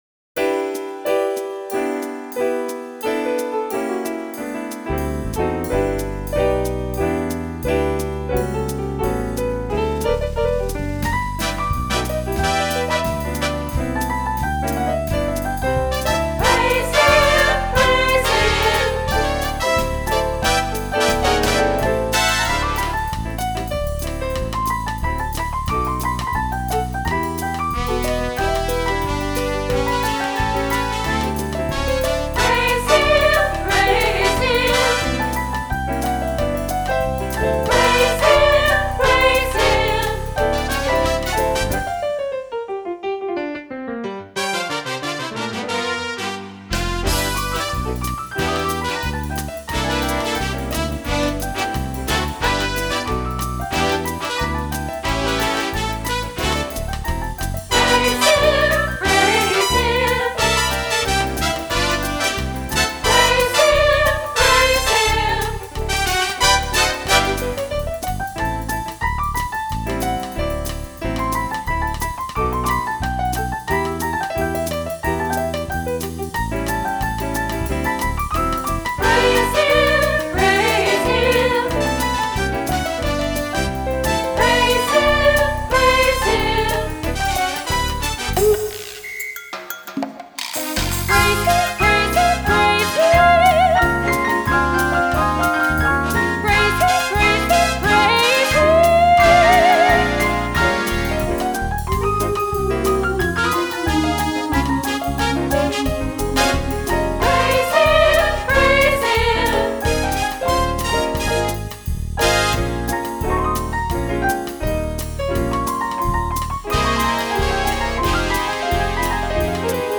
Fanny J. CrosbyOne of my favorite Fanny J. Crosby songs is the well-known hymn, “Praise Him, Praise Him” which I recorded a few years ago in a bit of an up-tempo jazzy version; I hope she would have approved!